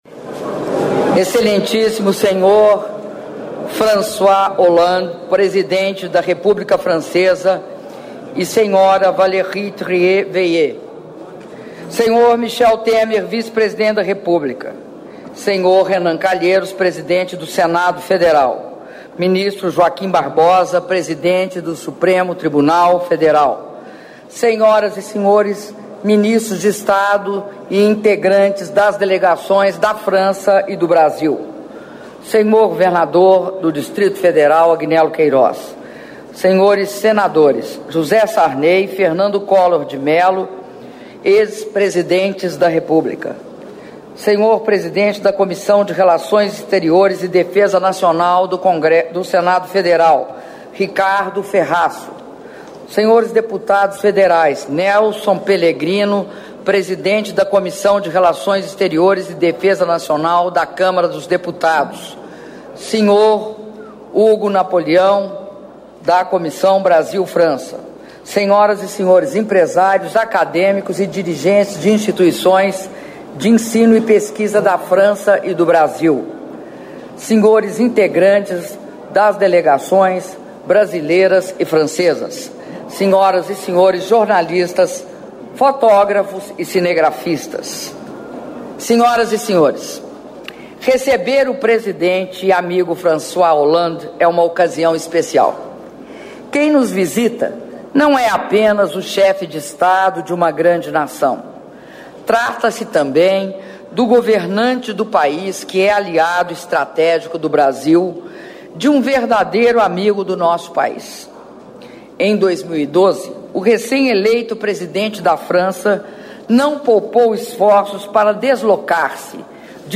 Áudio do brinde da Presidenta da República, Dilma Rousseff, durante almoço em homenagem ao presidente da República francesa, François Hollande, e senhora Valérie Trierweiler